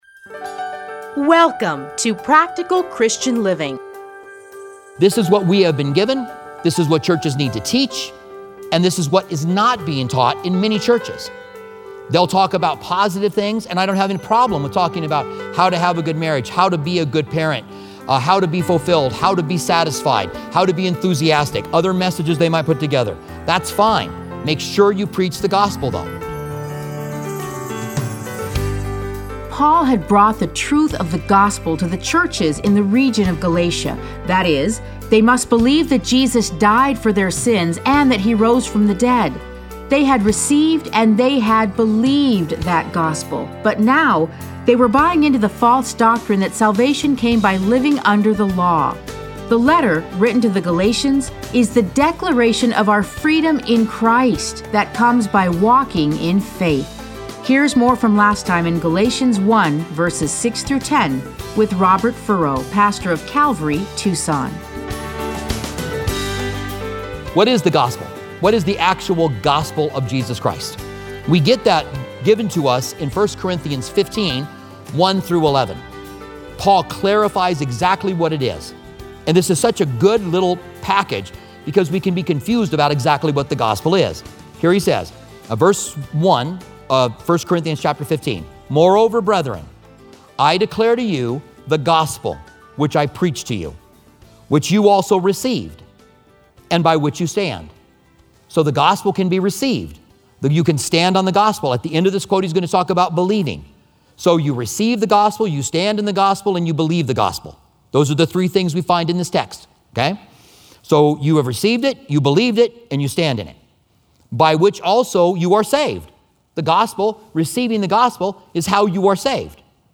Listen to a teaching from Galatians 1:6-10.